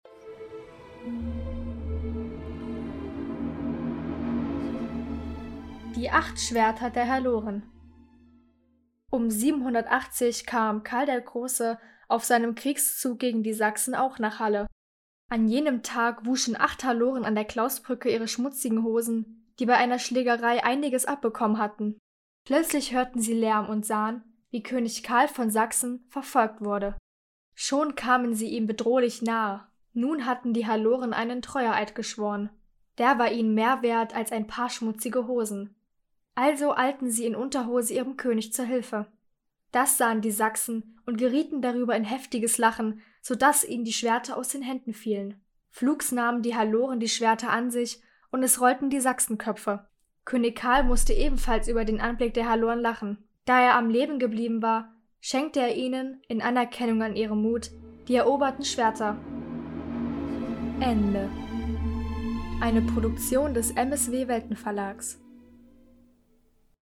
Eine Sage aus Halle (Saale) vorgelesen von der Salzmagd des